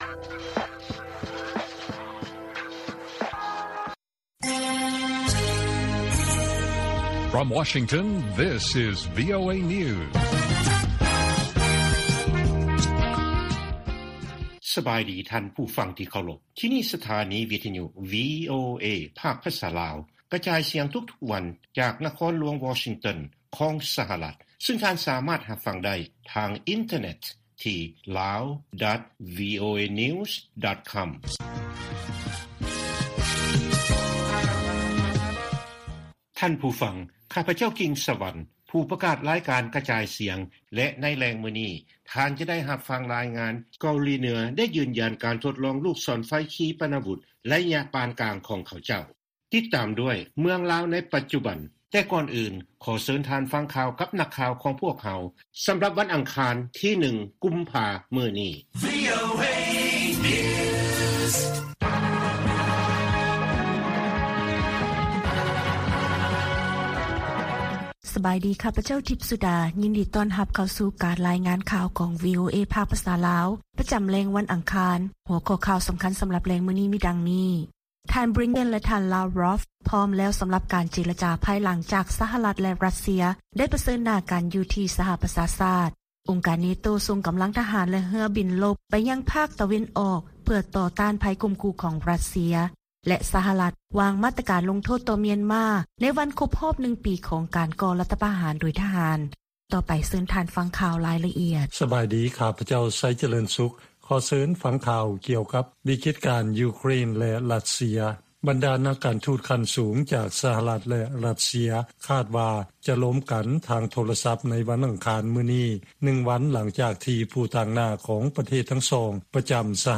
ລາຍການກະຈາຍສຽງຂອງວີໂອເອ ລາວ: ທ່ານບລິງເກັນ ແລະທ່ານລາວຣັອຟ ພ້ອມແລ້ວສຳລັບການເຈລະຈາ ພາຍຫຼັງຈາກ ສະຫະລັດ ແລະຣັດເຊຍ ໄດ້ປະເຊີນໜ້າກັນຢູ່ທີ່ສະຫະປະຊາຊາດ